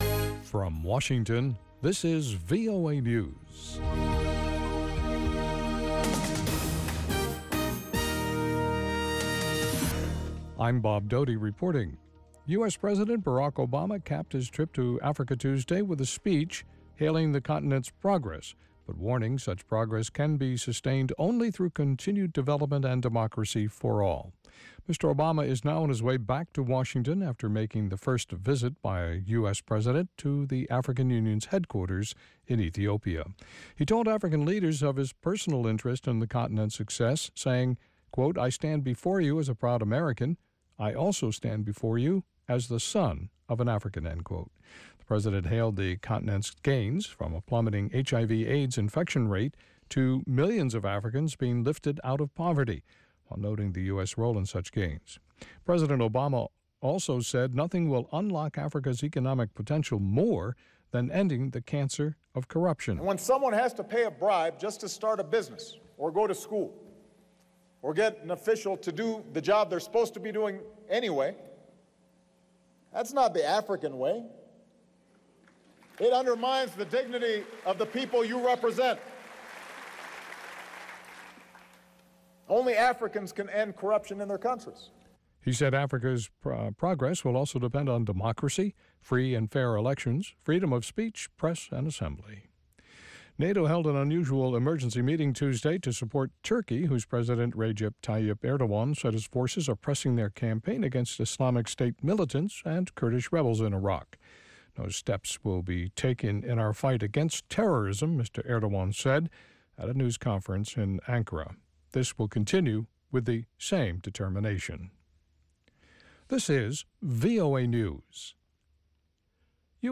Africa News Tonight is a lively news magazine show featuring VOA correspondent reports, interviews with African officials, opposition leaders, NGOs and human rights activists.
Music and the popular sports segment, Sonny Side of Sports, round out the show.